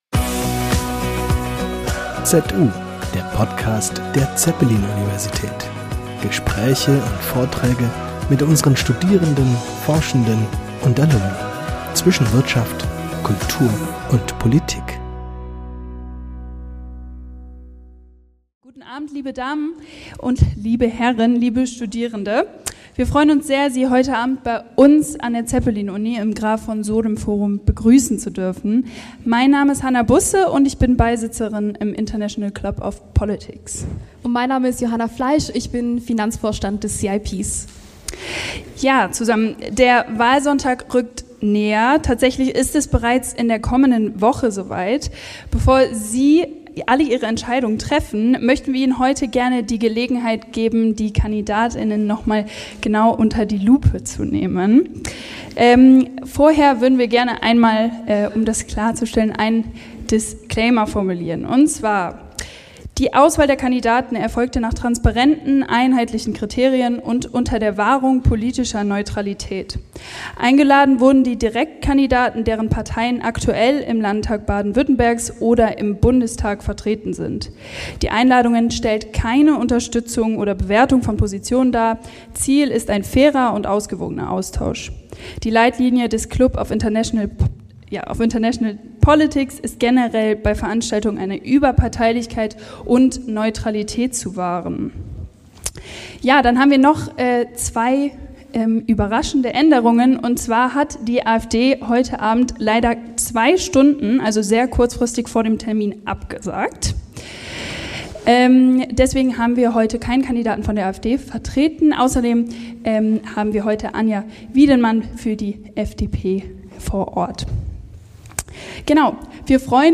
Podiumsdiskussion zur Landtagswahl in Baden-Württemberg ~ ZU - Der Podcast der Zeppelin Universität Podcast
Zur Landtagswahl in Baden-Württemberg am 8. März hatte der Club of International Politics e.V. zu einer Podiumsdiskussion mit vier Direktkandidaten aus dem Bodenseekreis und einer Direktkandidatin aus dem Wahlkreis Ravensburg eingeladen.
Die Veranstaltung bot dem Publikum eine einmalige Gelegenheit, politische Inhalte kritisch zu hinterfragen, unterschiedliche Perspektiven kennenzulernen, mit den Direktkandidaten ins Gespräch zu kommen und sich so kurz vor der Landtagswahl ein eigenes Bild zu machen.